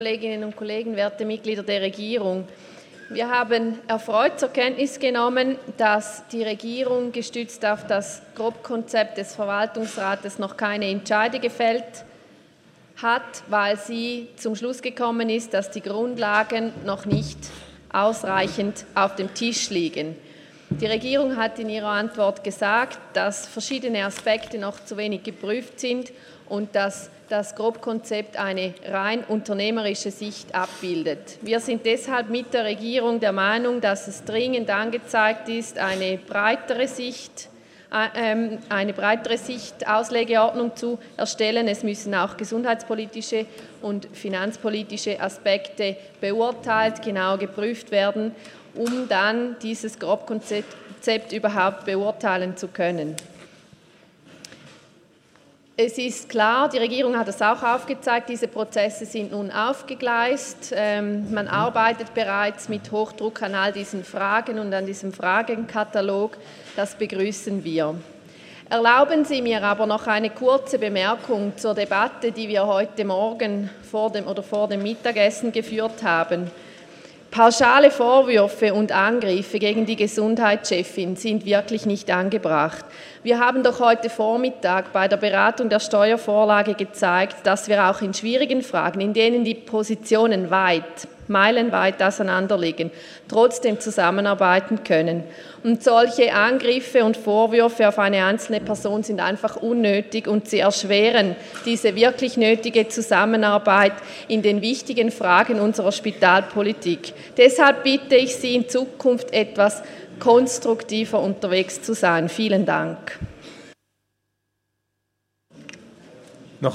27.11.2018Wortmeldung
Session des Kantonsrates vom 26. bis 28. November 2018